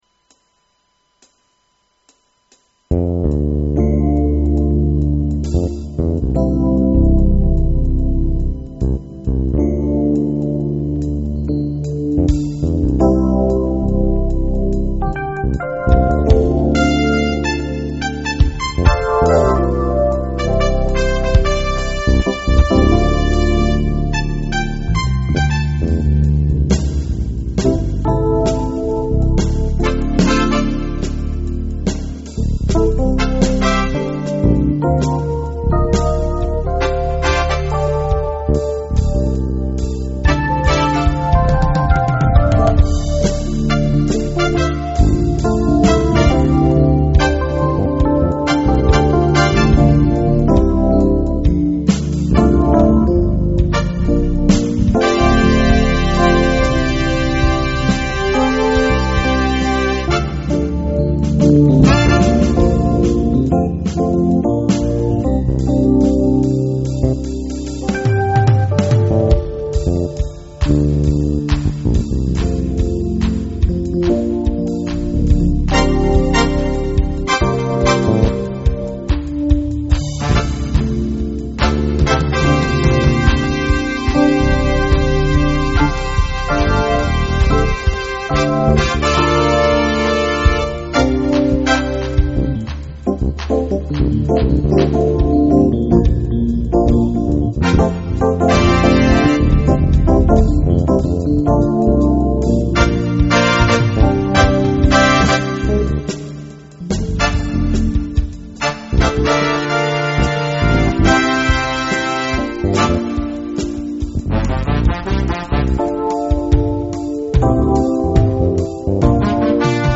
the same + tv reverb and track mix compression.